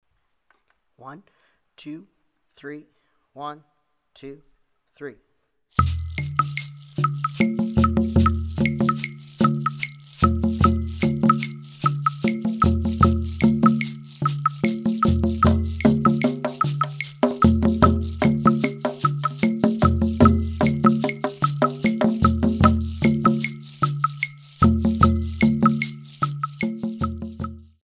combined with traditional drum music from West Africa.
The music combines various percussion instruments,
Slow Triple Meter
Slow Triple Meter - 75 bpm